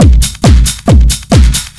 ELECTRO 09-R.wav